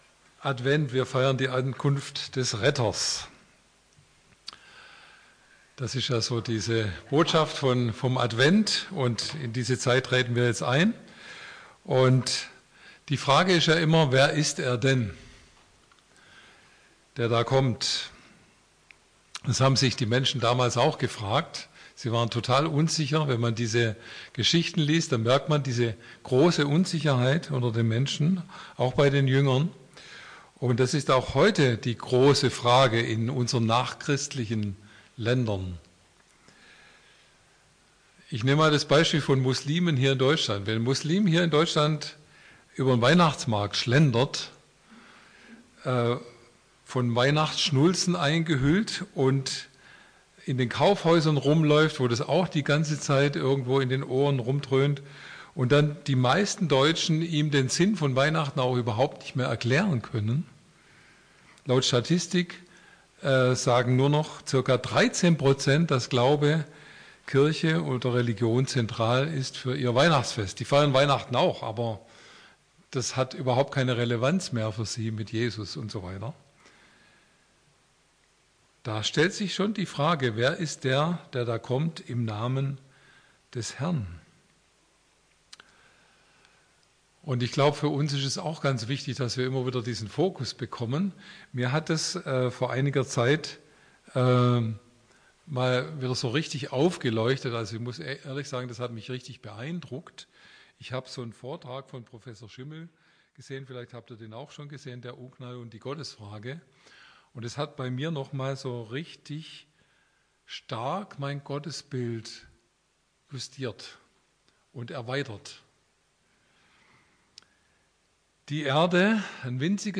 Unsere Erwartungen und Gottes Handeln ~ Predigten aus der Fuggi Podcast